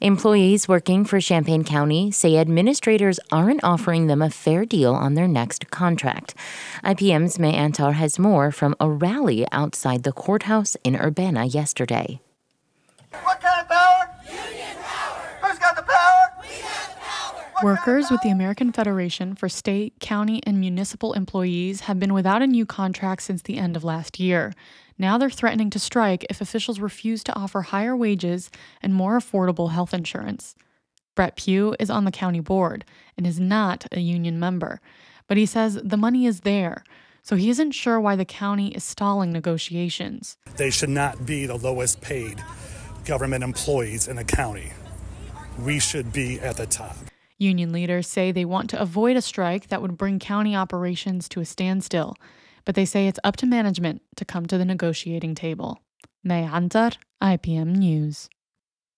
Over 100 people gathered outside the Champaign County Courthouse Monday to demand fair wages.
A protester holds a bullhorn as others hold up signs for the rally.
County-rally-audio.mp3